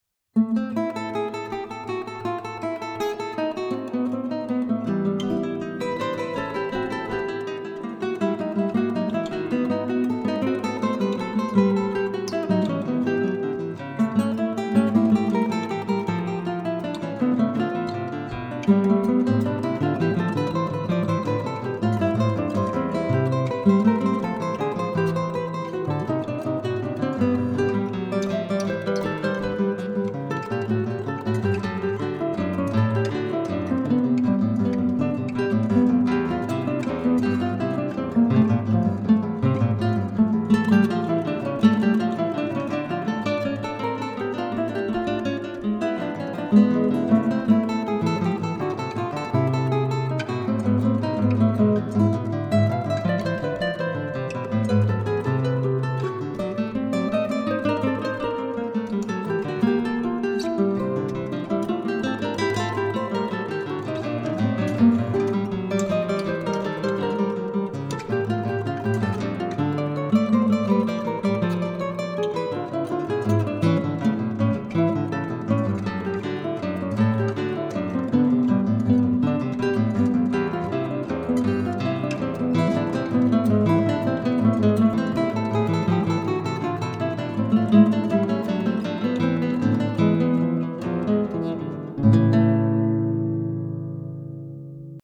У меня явственнее звучат дисканты, а вторая партия как-то глуховато.
А у меня все более-менее сбалансированно звучит.
Да вроде так же, просто как-то немного поярче.
fuga_10.mp3